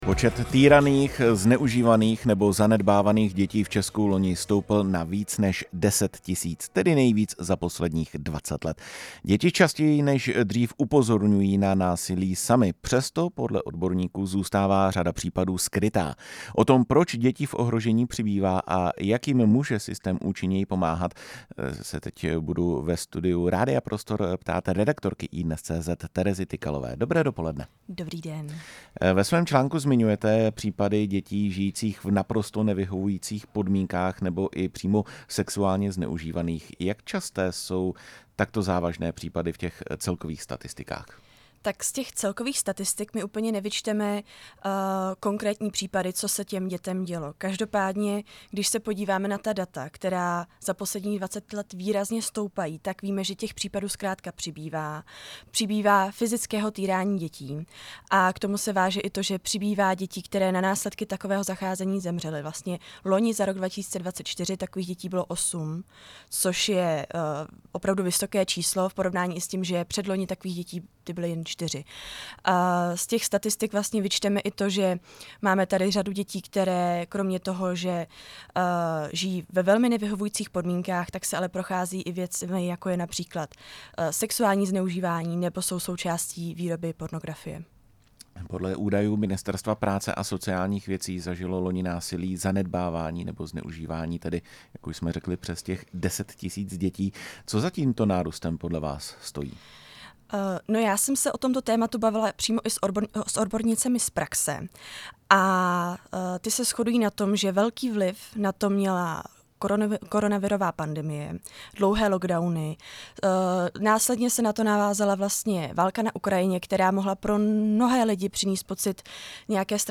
mluvila u nás ve studiu
Rozhovor